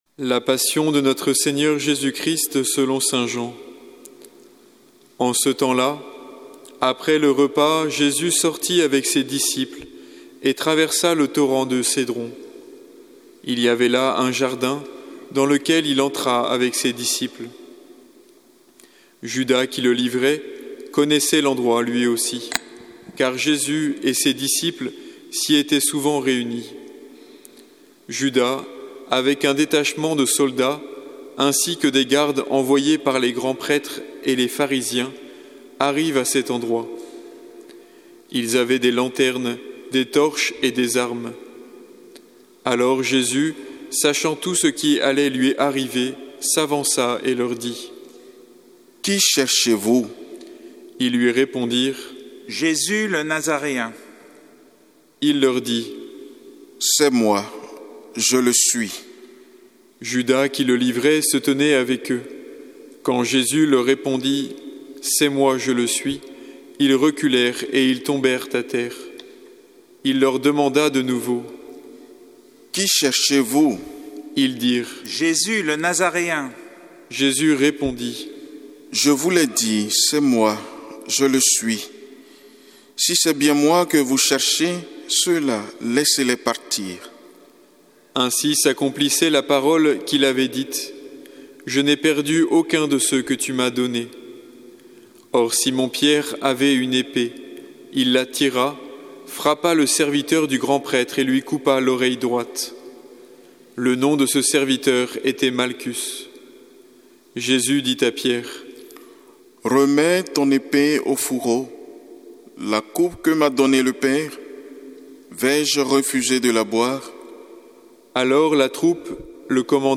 La Passion de notre Seigneur Jésus Christ selon saint Jean avec l'homélie